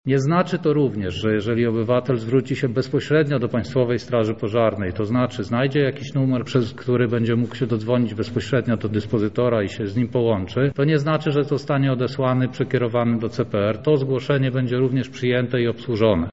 • mówi lubelski komendant wojewódzki Państwowej Straży Pożarnej gen. Grzegorz Alinowski.